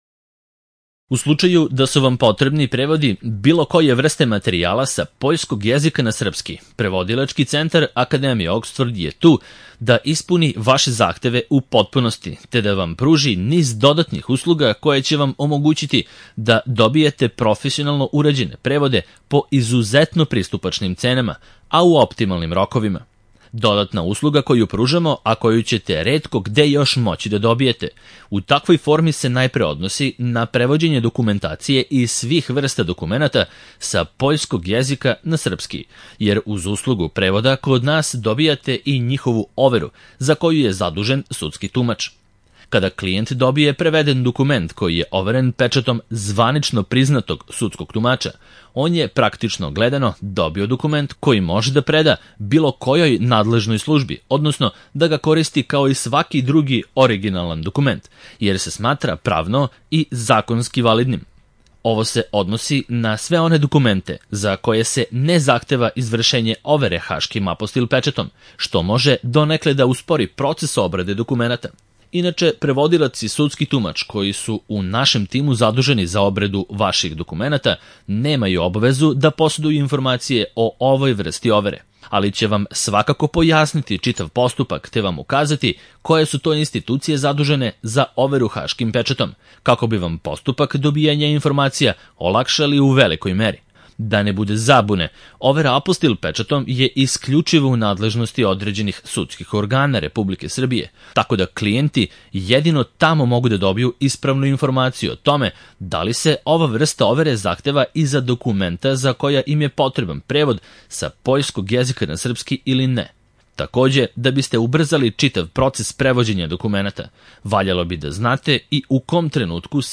Prevodi sa poljskog na srpski jezik - Audio verzija